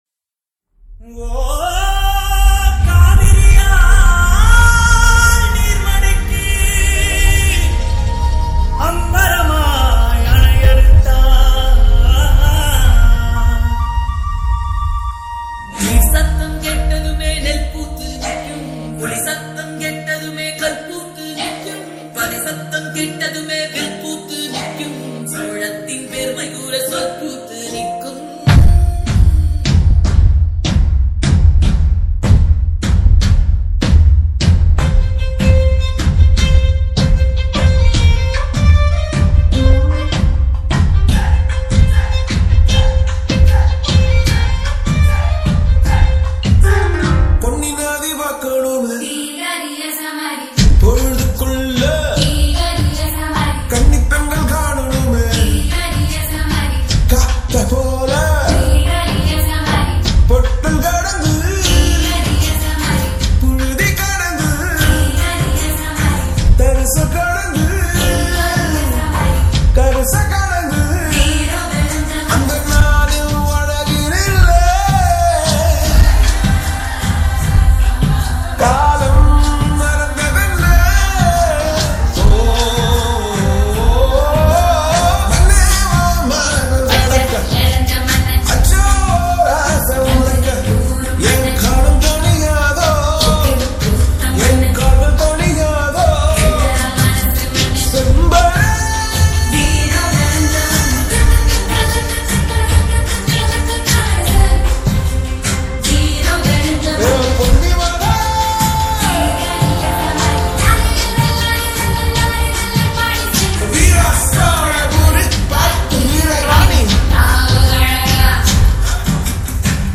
ALL TAMIL DJ REMIX » Tamil 8D Songs